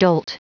Prononciation du mot dolt en anglais (fichier audio)
Prononciation du mot : dolt